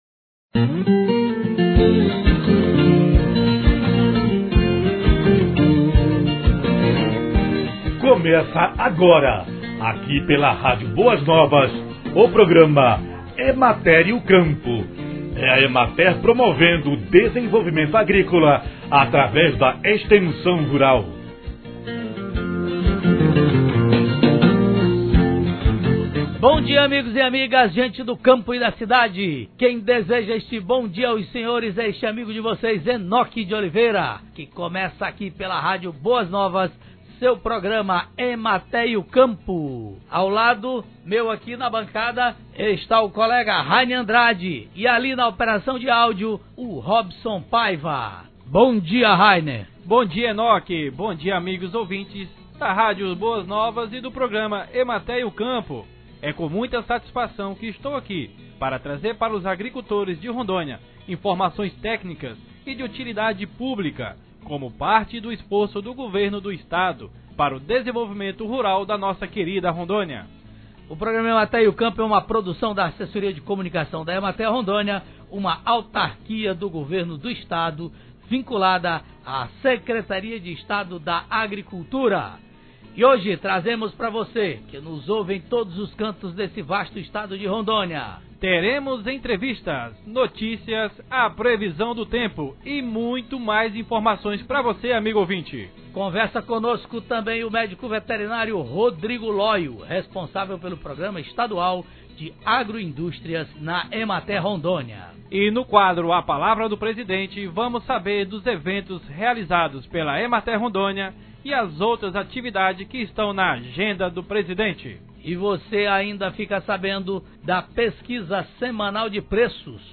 Programa de Rádio